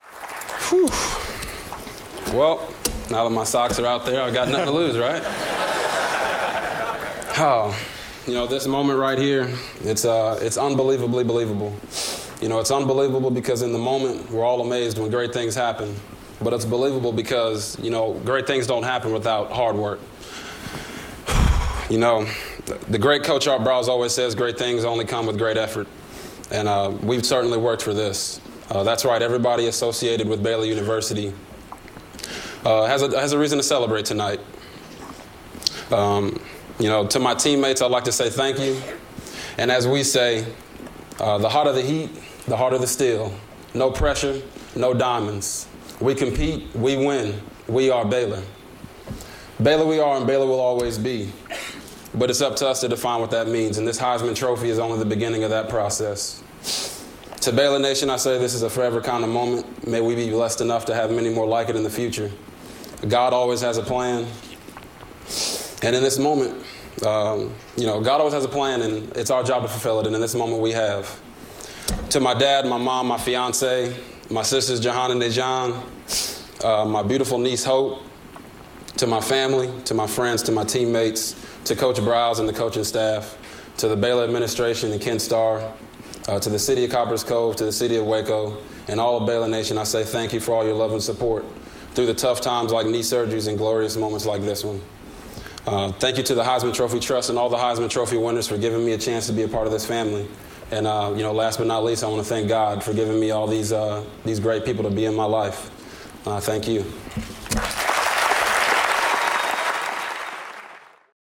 Heisman Trophy Award Acceptance Address
delivered day 10 December 2011, Times Square, New York
robertgriffinheismantrophyacceptance.mp3